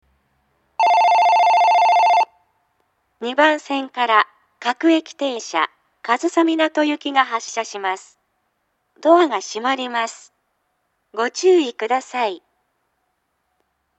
この駅は発車時にメロディーではなく、電鈴からベルが流れます。
ただし、放送装置更新後は電子電鈴装置が撤去され、ベルはスピーカーから流れる“ROMベル”となりました。
２番線発車ベル 発車放送は各駅停車上総湊行です。